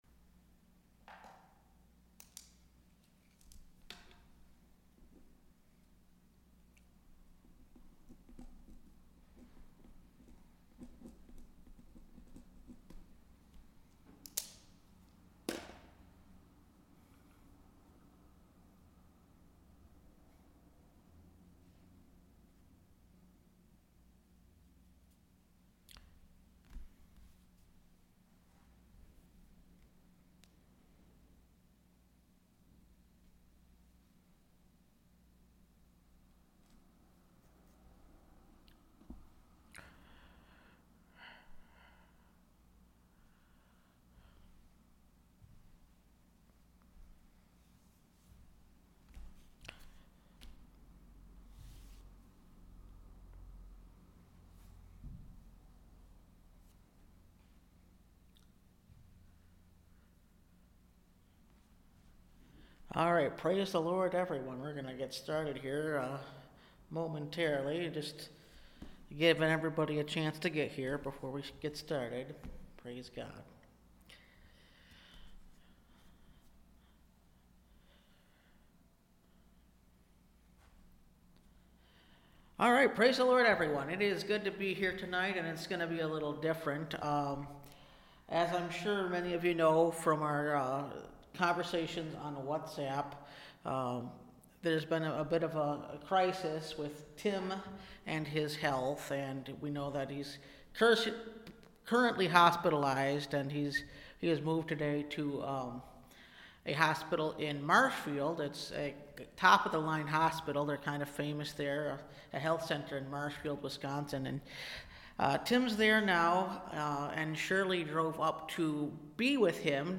Resisting The Devil – Last Trumpet Ministries – Truth Tabernacle – Sermon Library